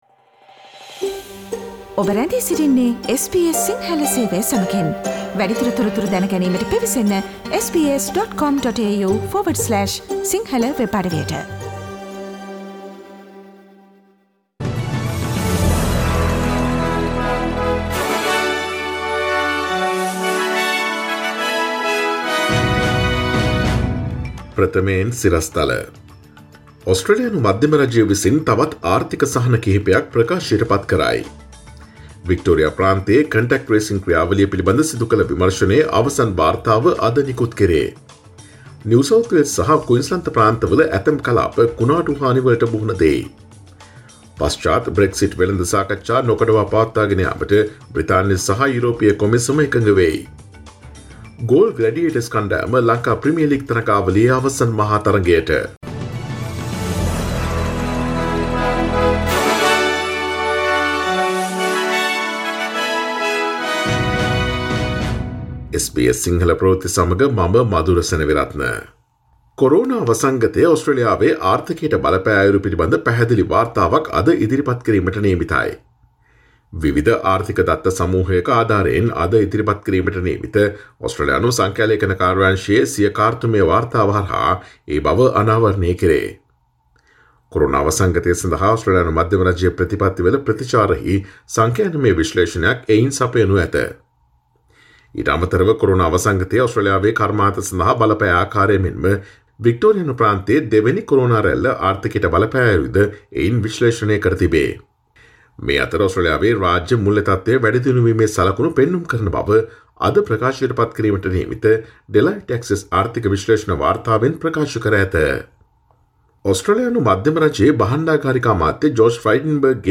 Today’s news bulletin of SBS Sinhala radio – Monday 14 December 2020